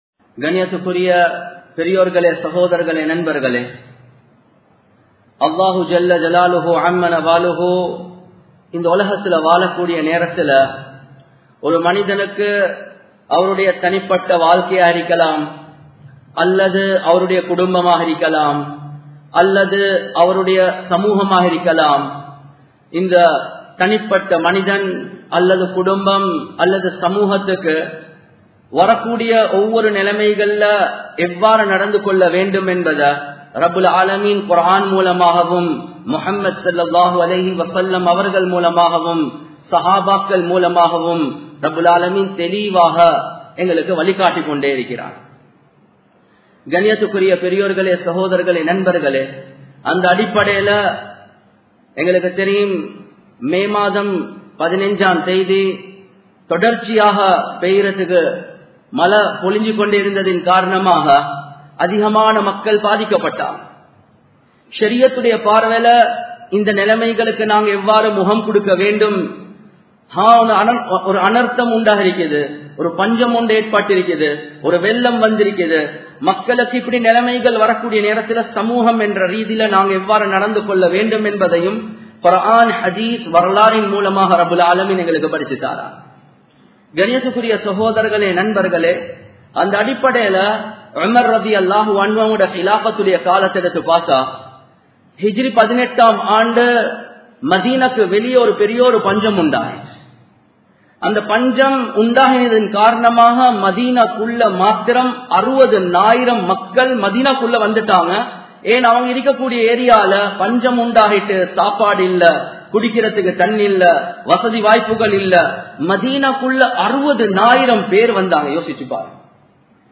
Paathikka Patta Makkalukku Uthavungal (பாதிக்கப்பட்ட மக்களுக்கு உதவுங்கள்) | Audio Bayans | All Ceylon Muslim Youth Community | Addalaichenai
Samman Kottu Jumua Masjith (Red Masjith)